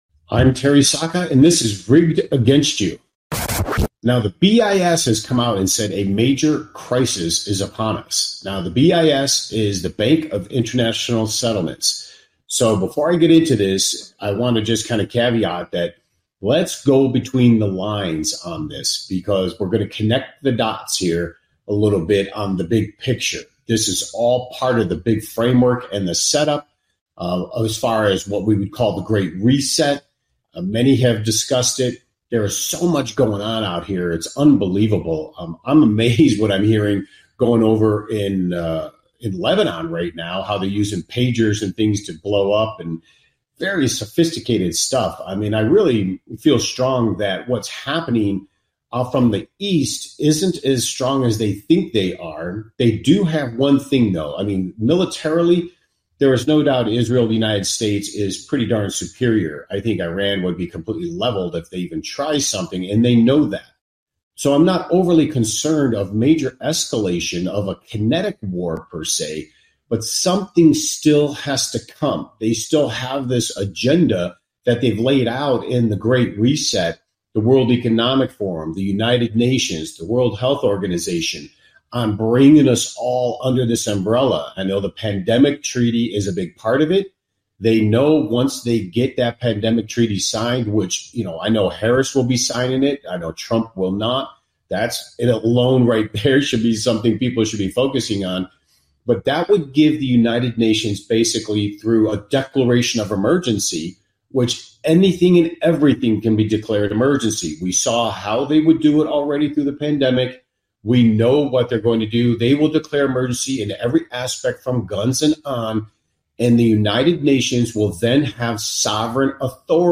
Rigged Against You Talk Show